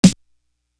D Elite Snare.wav